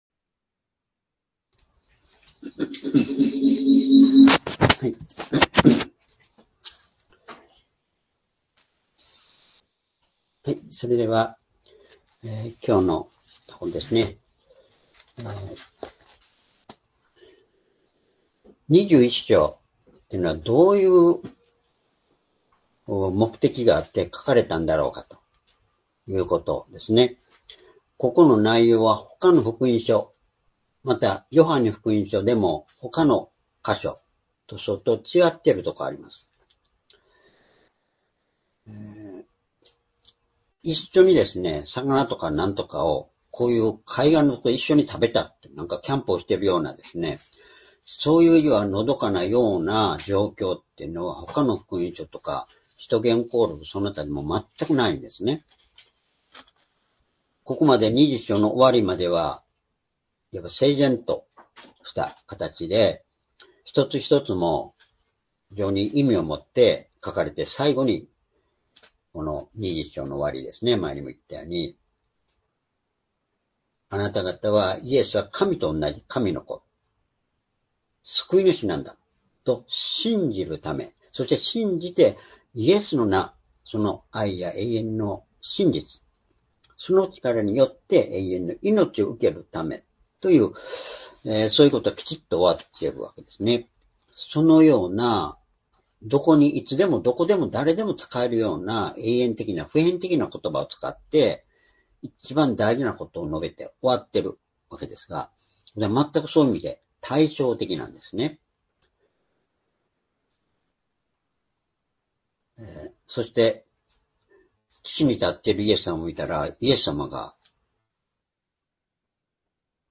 主日礼拝日時 ２０２５年６月１日 聖書講話箇所 「イエスの愛と励まし」 ヨハネ21の５－１４ ※視聴できない場合は をクリックしてください。